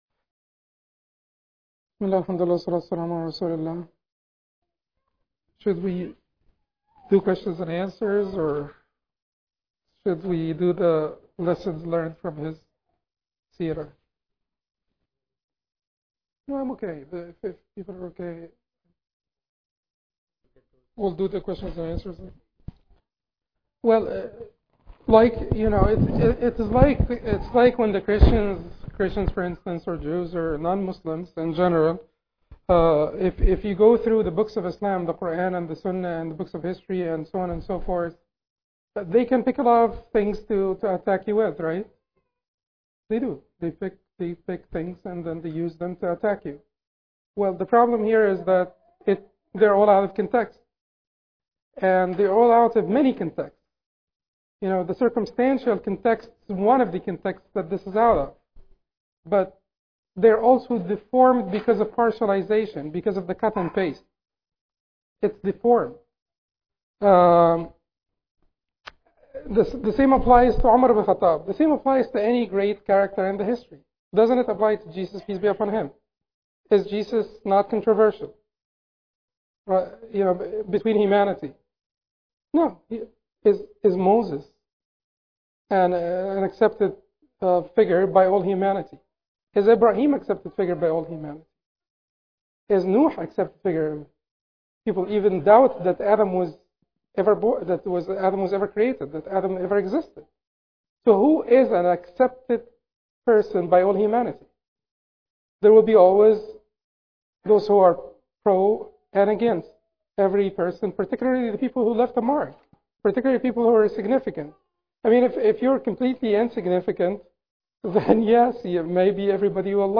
Al-Farooq: A Lecture on the Life of Umar ibn al Khattab – Part 2
An educational lecture on one of the most significant figures in Islamic History: the Prophet’s (s.a.w) close companion and second khalifa of the Islamic state, Umar ibn Khattab (r.a)Q&A